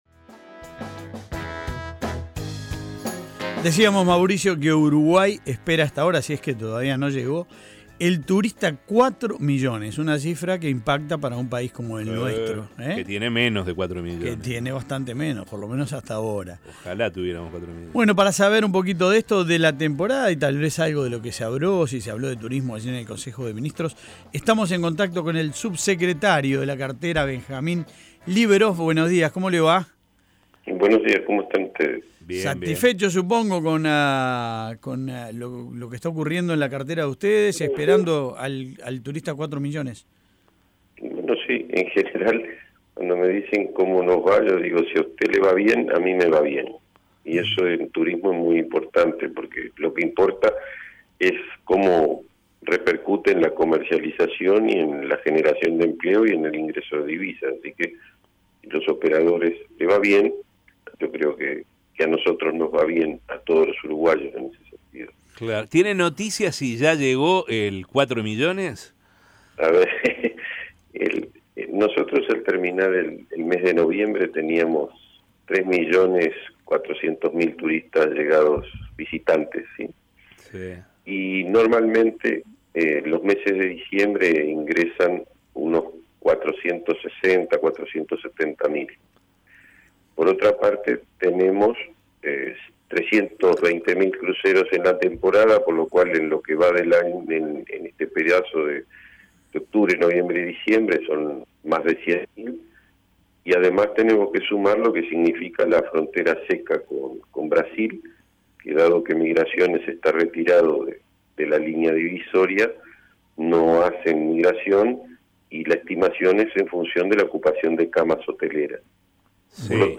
En diálogo con La Mañana de El Espectador, el funcionario manifestó que entre los días 29, 30 y 31 ingresan miles de turistas y que en esos días puede llegar el visitante número 4 millones.